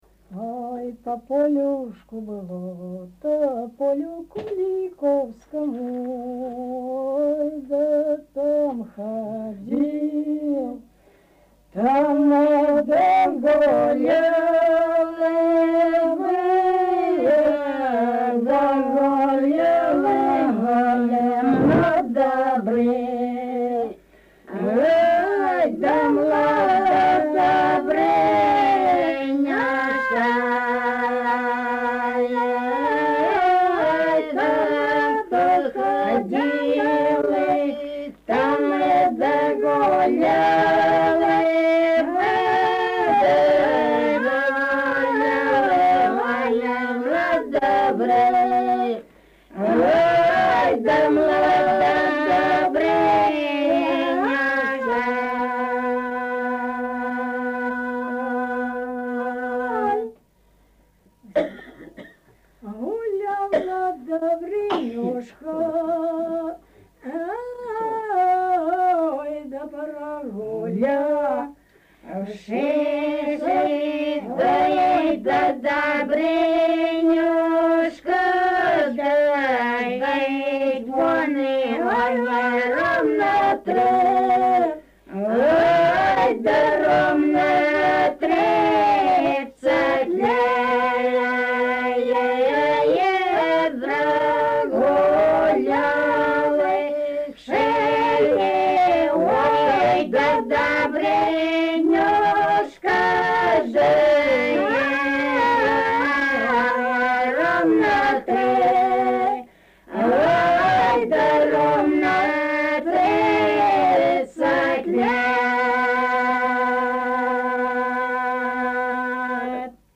Однако напевы, исполняемые многоголосно и в протяжной форме, преобладают.
Несмотря на использование протяжной формы распева, интонирование эпических песен существенно отличается от лирики. Это проявляется в скандированном произнесении предударных слогов (нередко на одном тоне) и переходе к вокальной позиции в мелодических вставках, отмечающих границы структурных единиц напева.
Своеобразие звучанию иногда придает тембровое смешение.
01 Былинная песня «По полюшку было